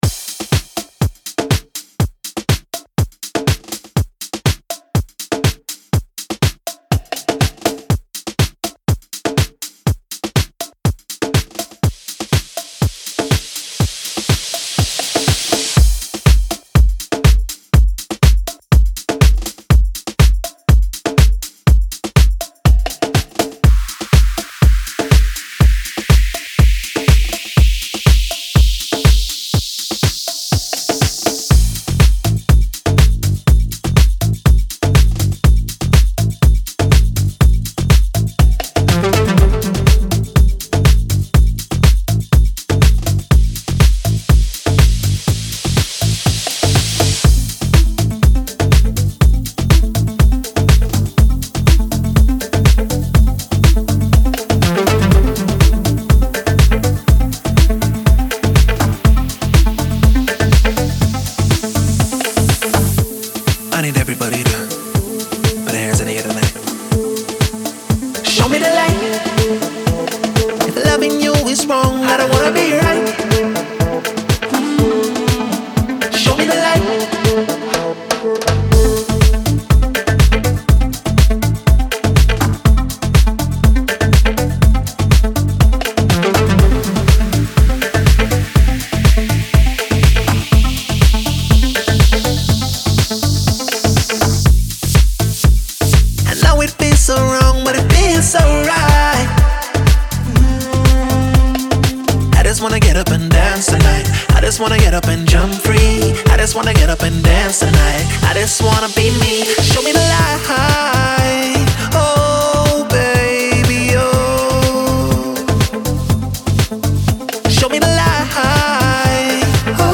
Жанр: Deep House, Future House, Dance